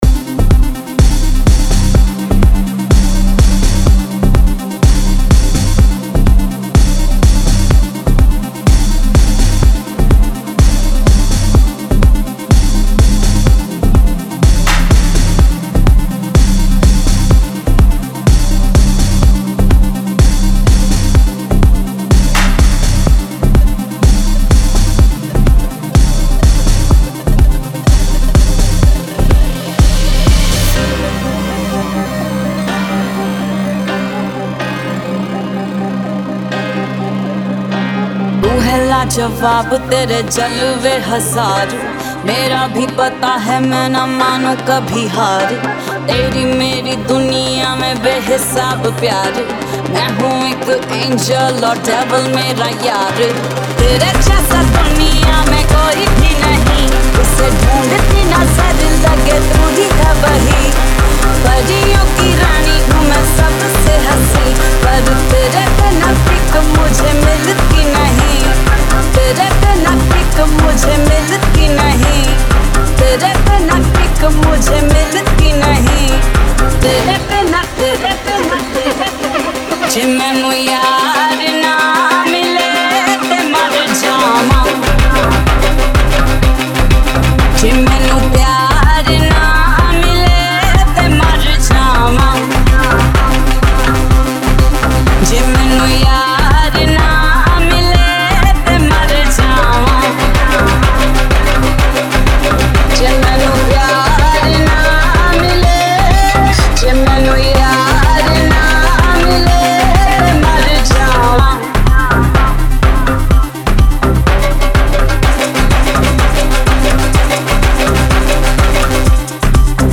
BOLLY AFRO